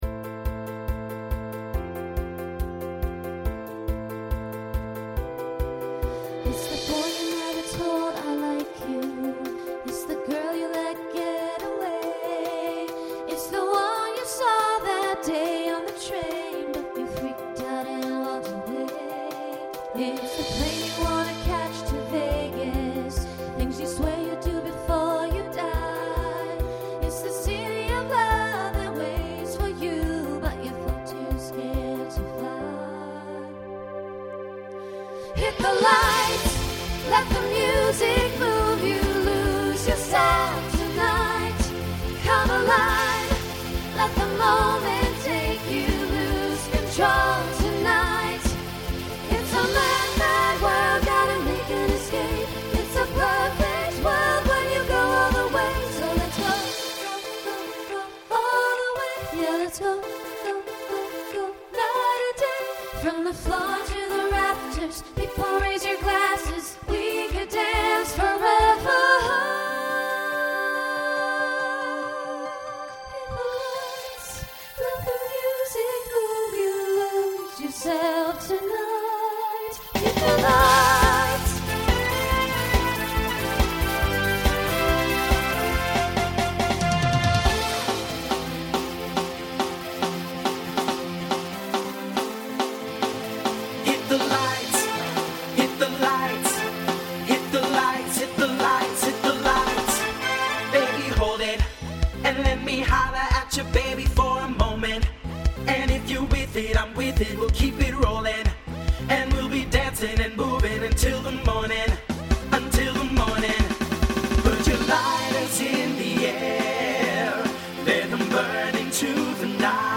SSA/TTB
Voicing Mixed Instrumental combo Genre Pop/Dance